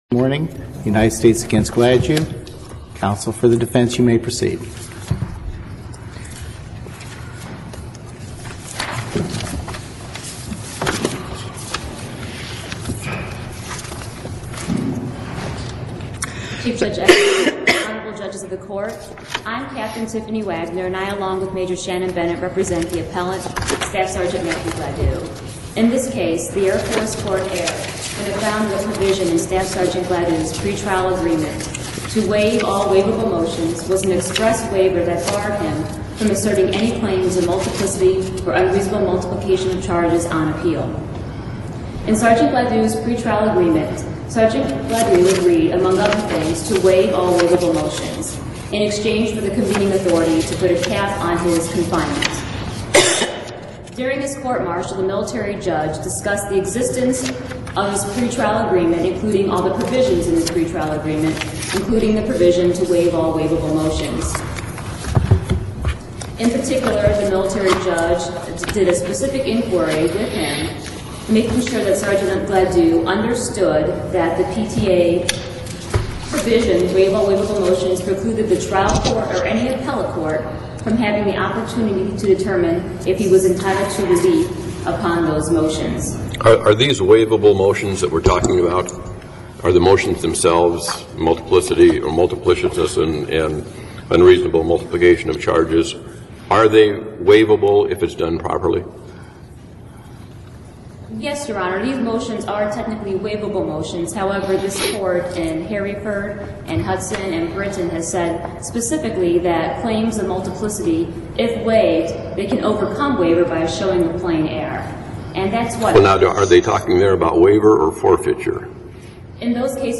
Audio for today's arguments posted